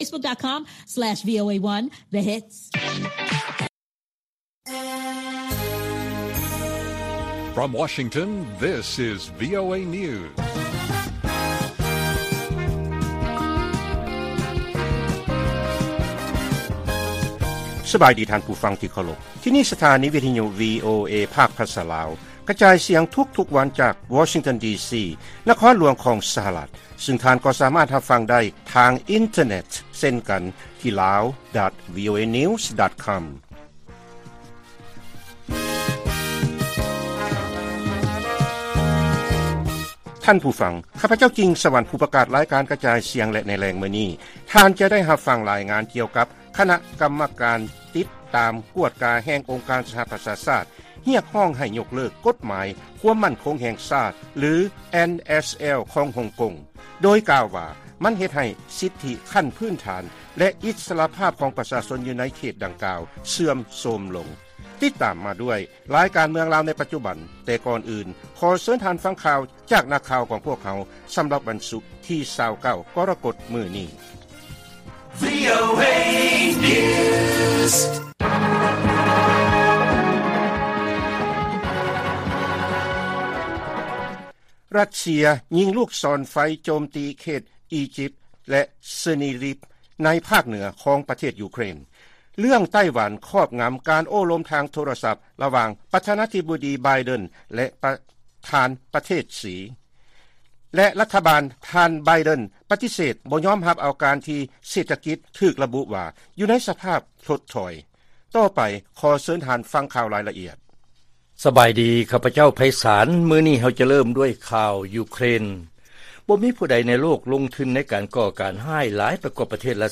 ລາຍການກະຈາຍສຽງຂອງວີໂອເອ ລາວ: ຣັດເຊຍ ຍິງລູກສອນໄຟໂຈມຕີ ເຂດກີຢິບ ແລະ ເຊີນີຮິບ